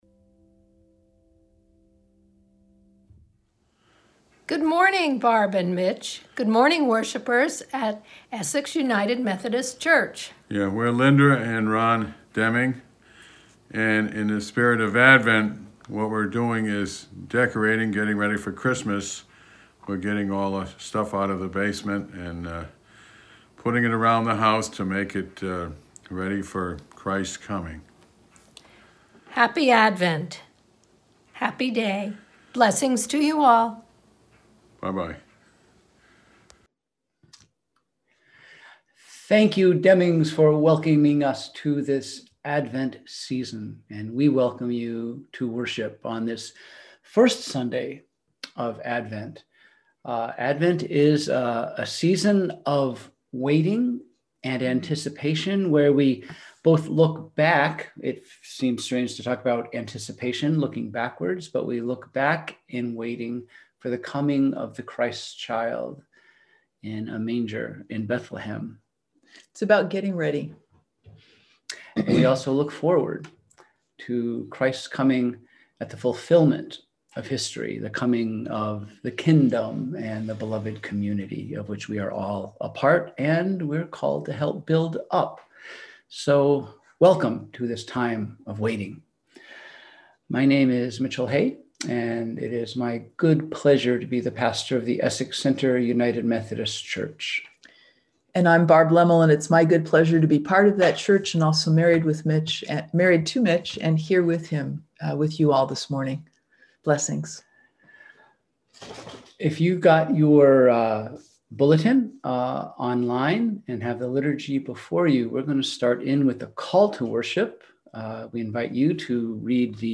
We held virtual worship on the first Sunday of Advent- November 29, 2020 at 10:00am.